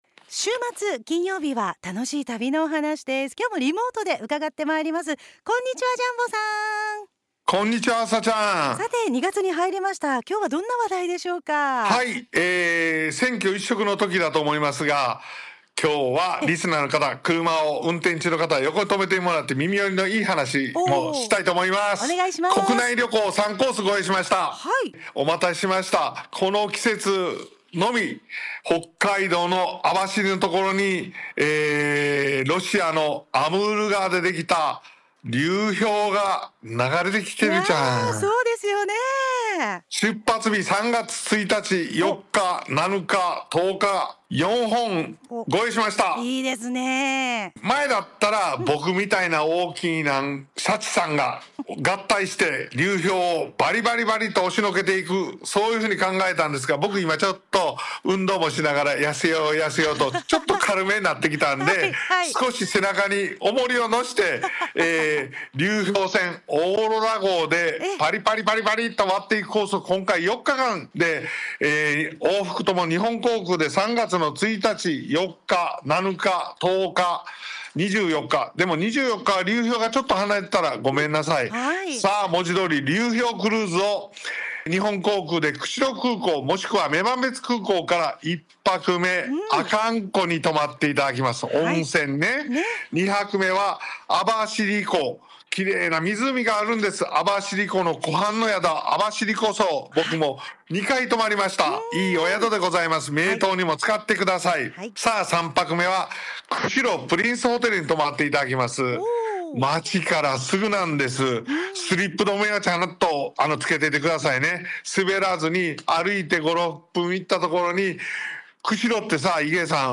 ★『〜大自然の芸術流氷に出逢う〜北海道流氷物語4日間／立山アルペンルート雪の大谷ウォークと白川郷を訪ねて／東北10の桜に魅せられて』2026年2月6日(金)ラジオ放送